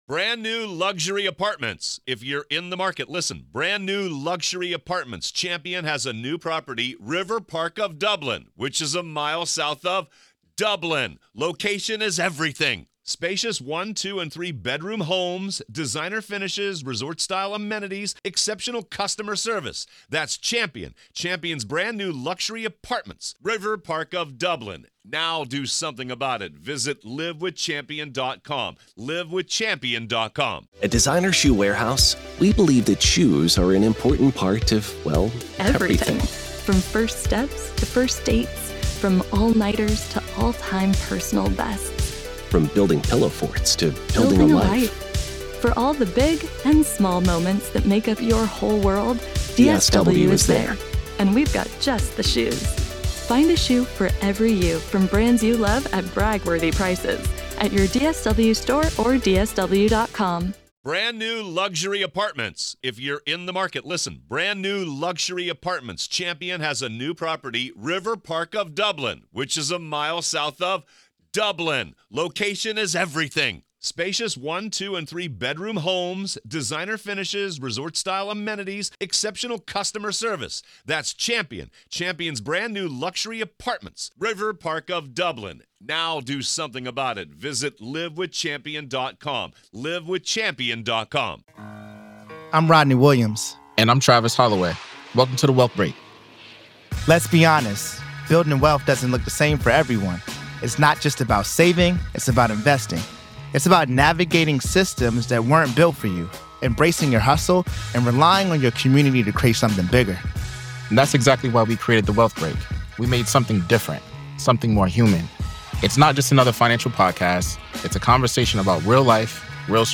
True Crime Today | Daily True Crime News & Interviews / Did Failed Careers Influence Chad Daybell’s Alleged Criminal Path?